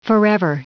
Prononciation du mot forever en anglais (fichier audio)
Prononciation du mot : forever